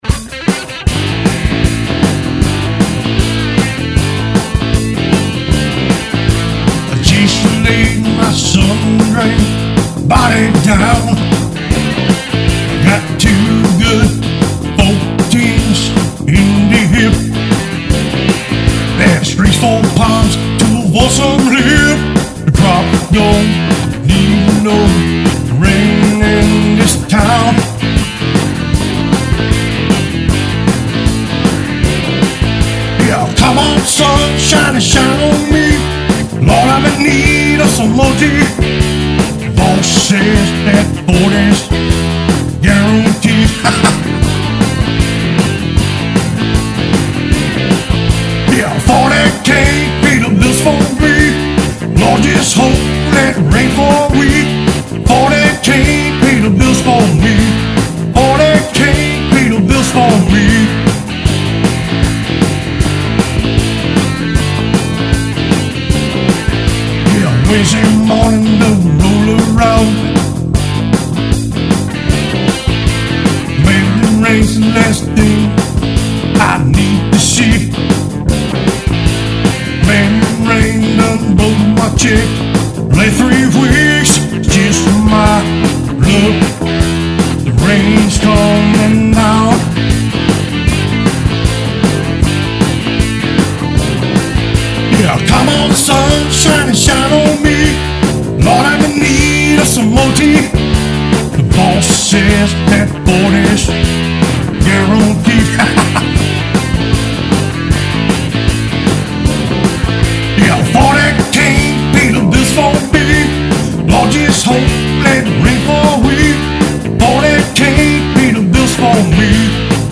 Guitar&Music